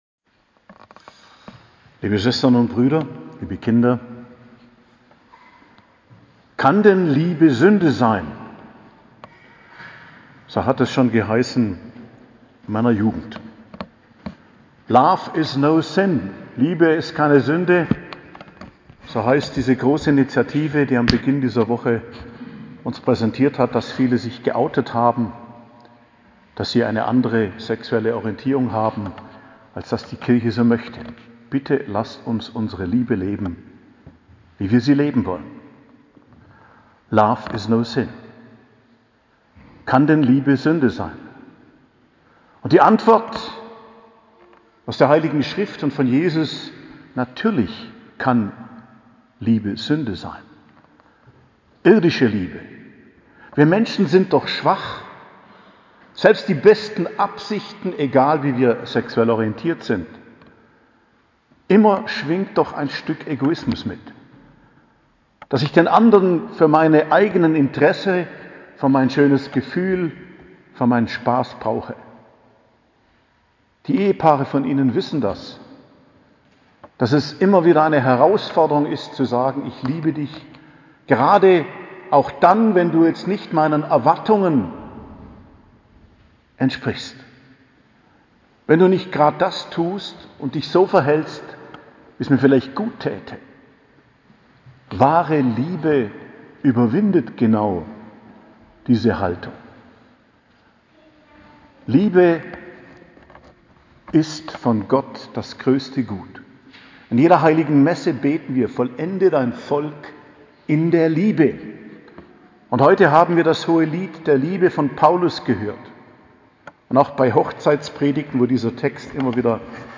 Predigt zum 4. Sonntag im Jahreskreis, 30.01.2022 ~ Geistliches Zentrum Kloster Heiligkreuztal Podcast